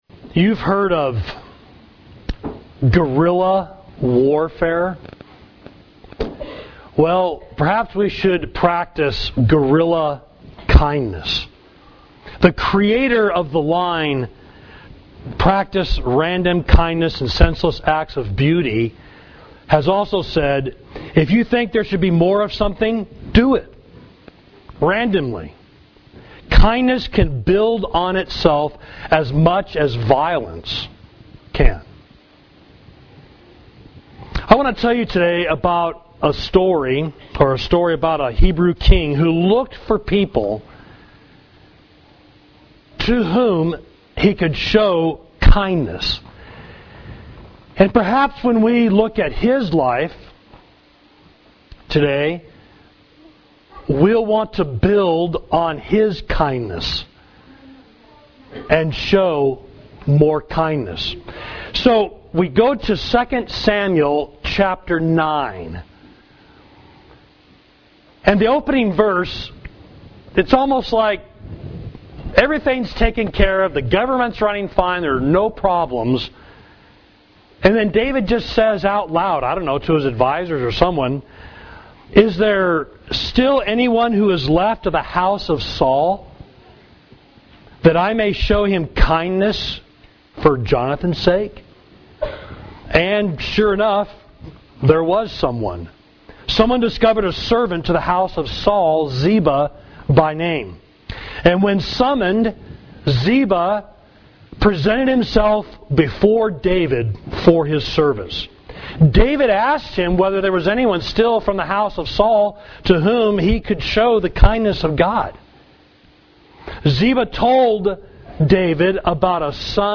Sermon: Be Zealous to Show Kindness, Second Samuel 9–10